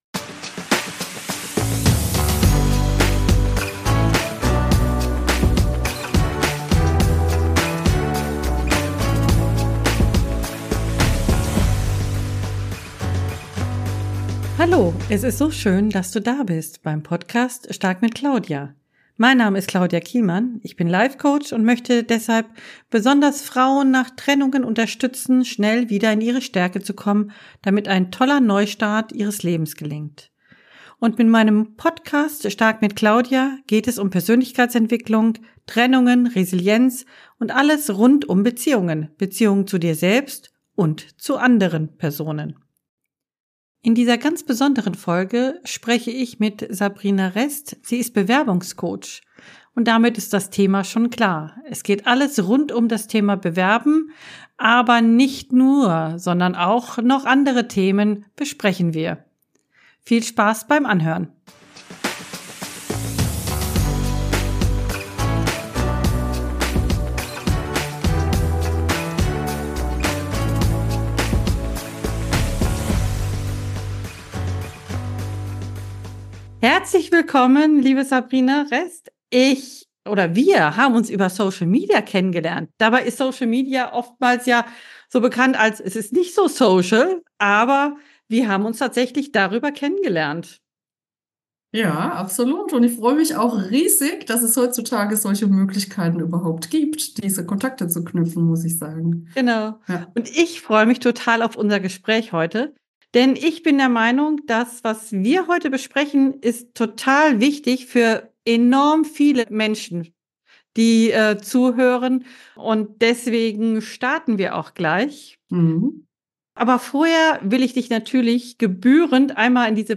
#041 So bekomme ich meinen Job - Interview